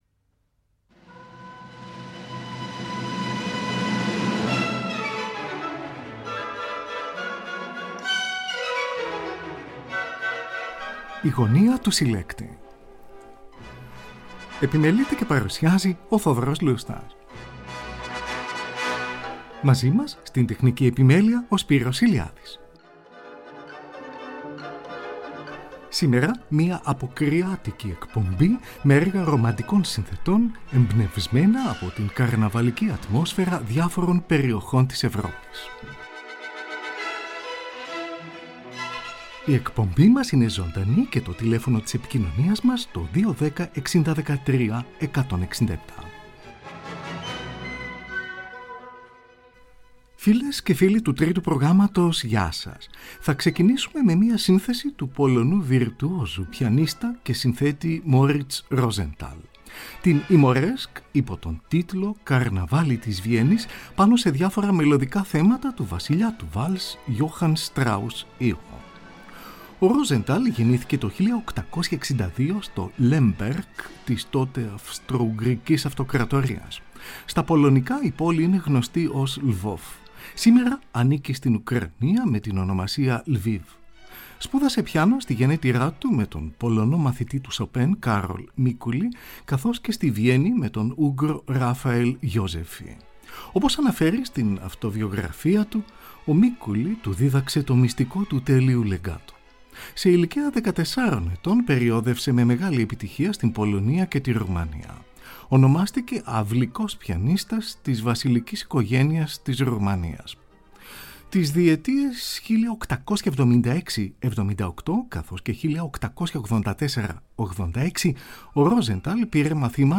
Συνθέσεις Εμπνευσμένες από το Καρναβάλι Moriz Rosenthal: «Καρναβάλι της Βιέννης». Antonín Dvořák: ορχηστρική εισαγωγή «Καρναβάλι», έργο 92, δεύτερο μέρος της τριλογίας με γενικό τίτλο Φύση, ζωή και έρωτας.
F ranz Liszt: «Ουγγρική ραψωδία» για πιάνο αρ.9 υπό τον τίτλο «Καρναβάλι στην Πέστη», στην ορχηστρική εκδοχή που επιμελήθηκε ο δεξιοτέχνης του φλάουτου, συνθέτης και μαθητής του Liszt, Franz Doppler .